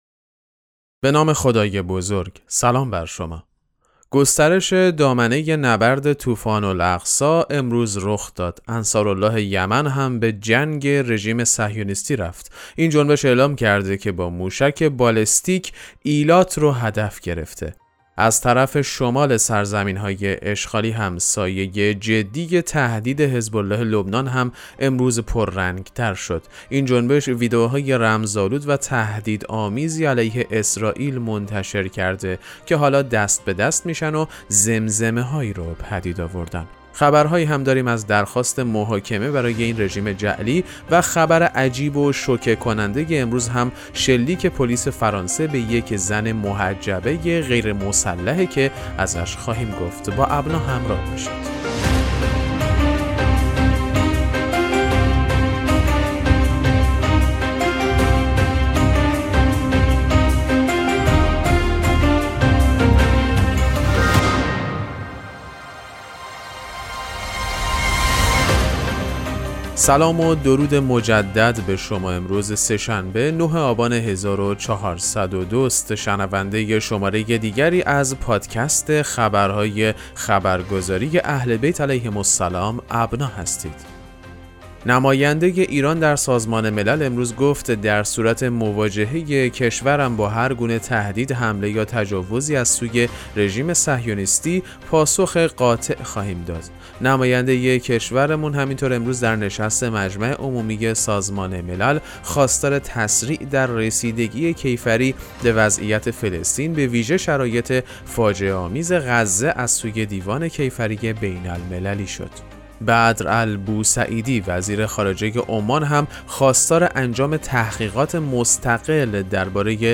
خبرگزاری اهل‌بیت(ع) ـ ابنا ـ با ارائه سرویس «پادکست مهم‌ترین اخبار» به مخاطبان خود این امکان را می‌دهد که در دقایقی کوتاه، از مهم‌ترین اخبار مرتبط با شیعیان جهان مطلع گردند.